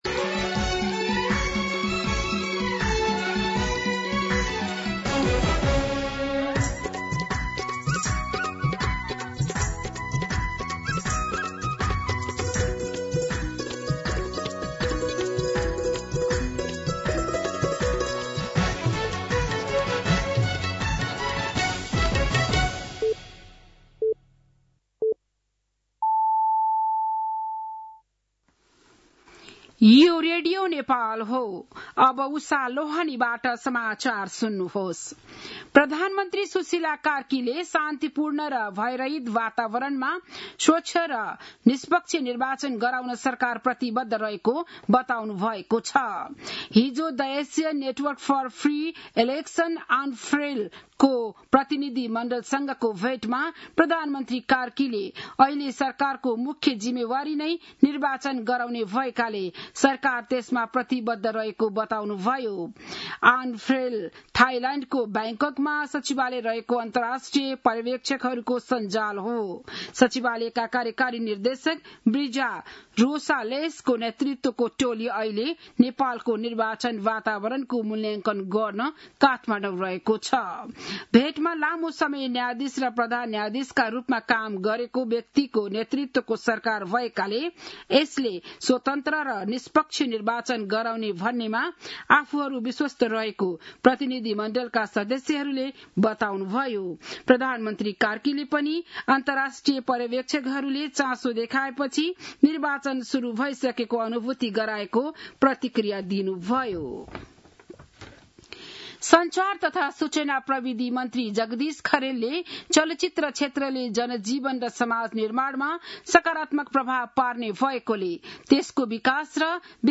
बिहान ११ बजेको नेपाली समाचार : २१ कार्तिक , २०८२